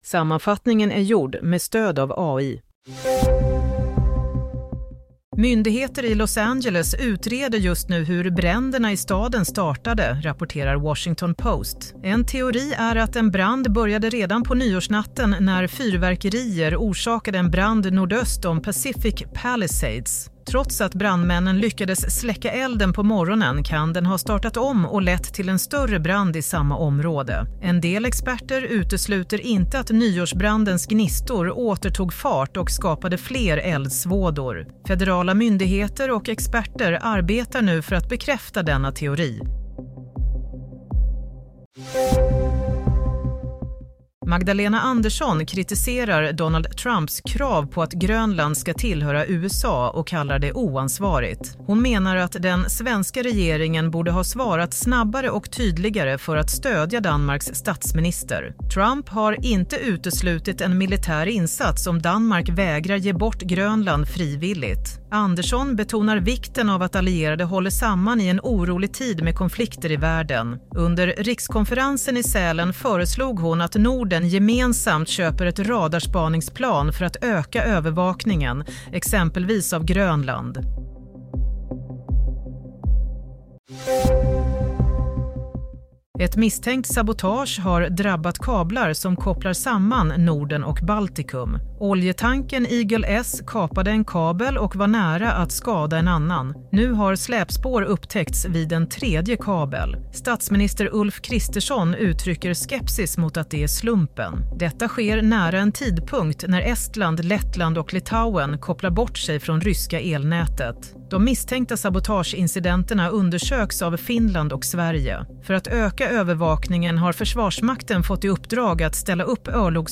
Nyhetssammanfattning – 12 januari 22:00